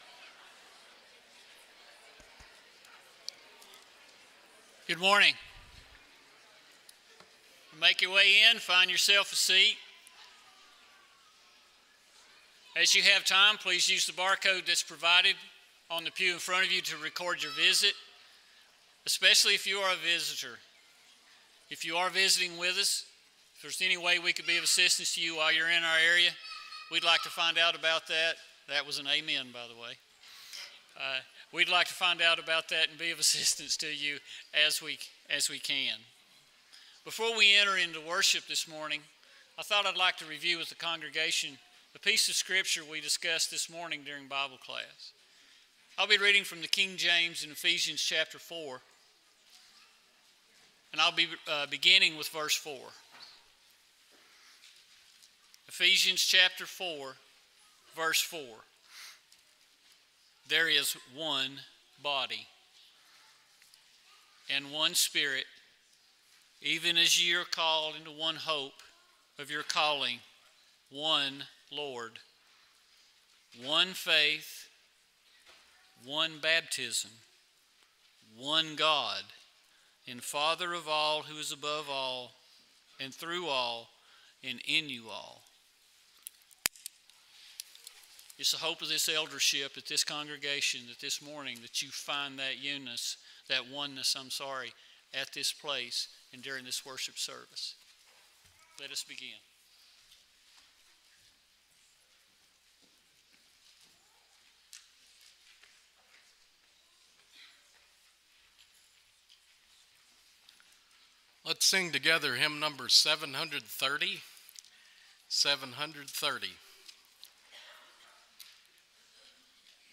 Matthew 4:17, English Standard Version Series: Sunday AM Service